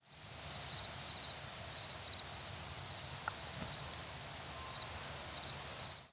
Yellow Warbler diurnal flight calls
Bird in flight.